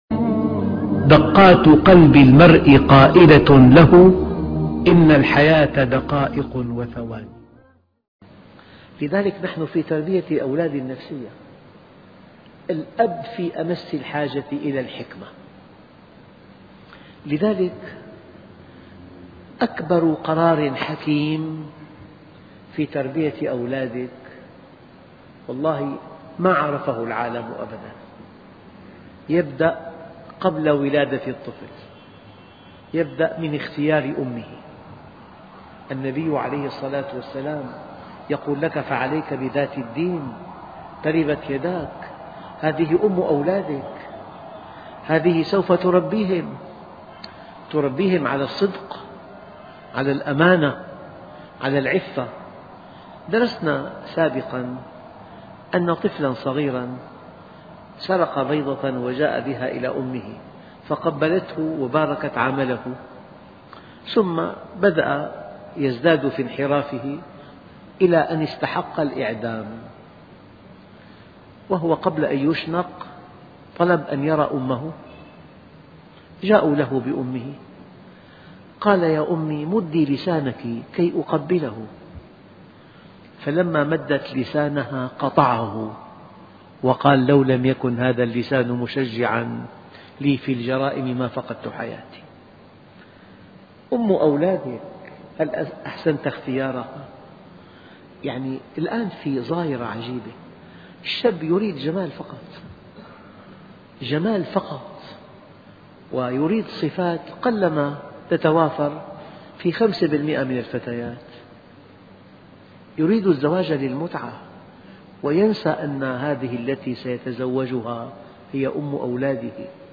الفوز بالجنة والنجاة من النار - دروس مختاره الجزء التاسع - الشيخ محمد راتب النابلسي